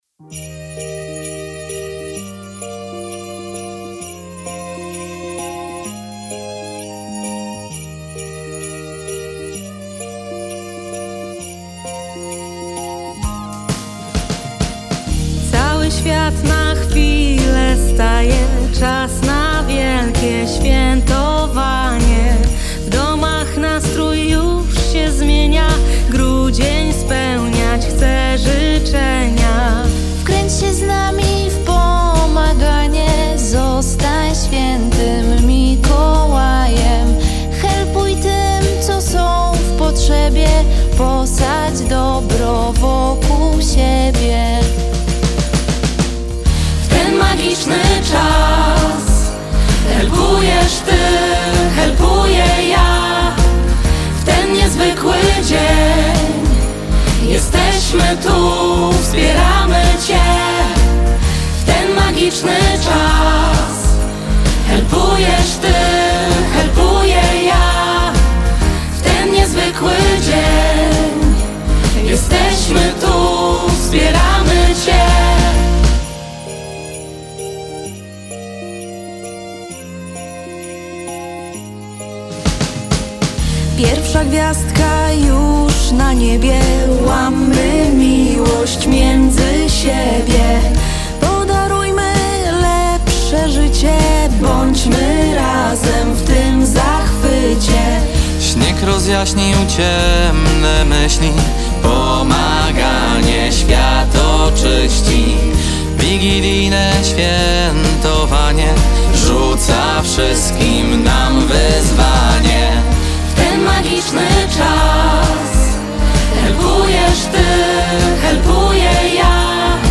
Piosenka mówi o pomaganiu z radością, bo helpowanie właśnie takie jest.
wokalistka